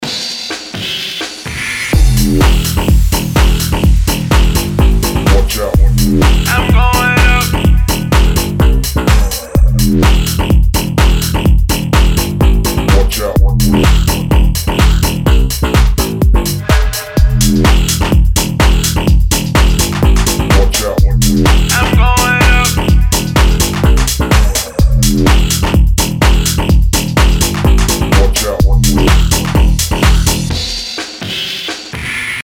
мощные
dance
future house
club
G-House
Стиль: Future House, G House